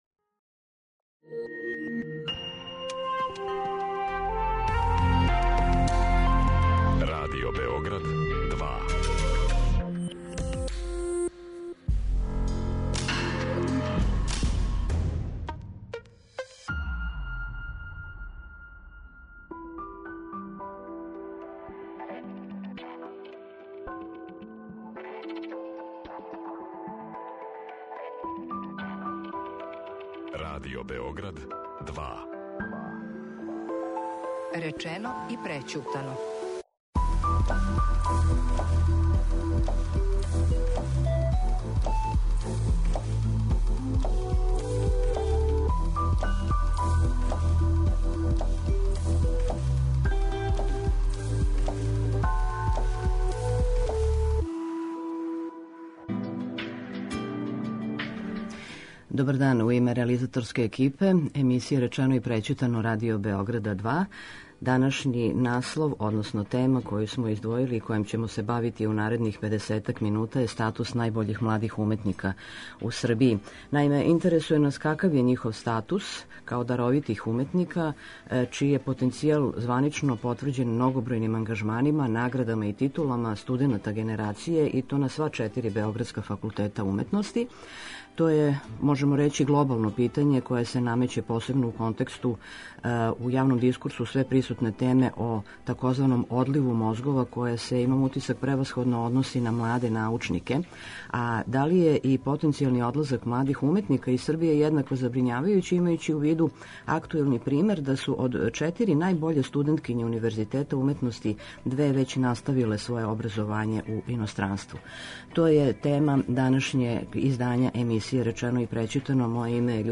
У данашњем термину биће репризирана емисија о статусу младих даровитих уметника у Србији, чији је потенцијал званично потврђен многобројним ангажманима, наградама и титулама студената генерације на сва четири београдска факултета уметности.